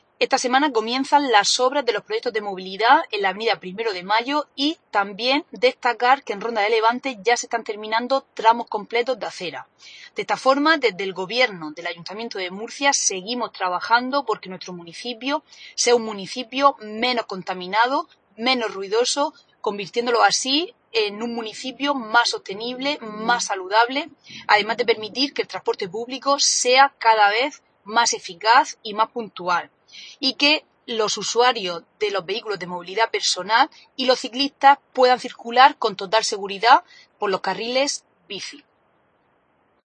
Carmen Fructuoso, concejala de Movilidad Sostenible y Limpieza Viaria.